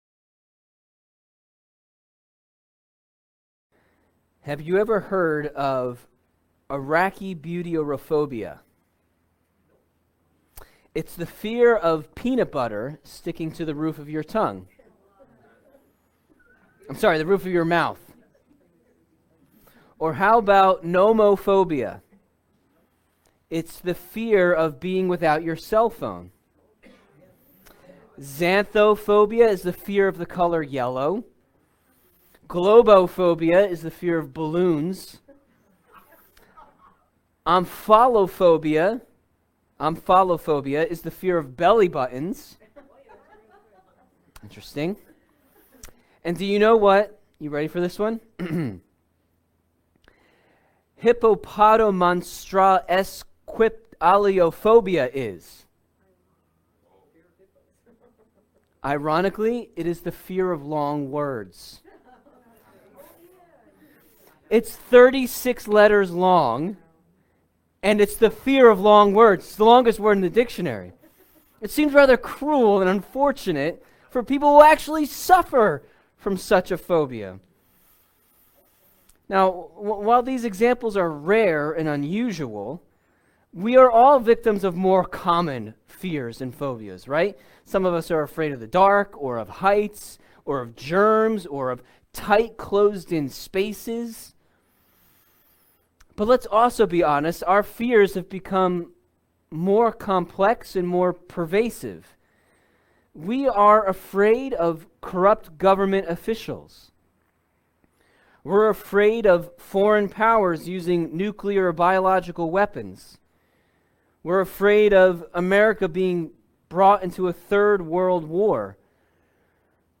Sermons | Faith Bible Church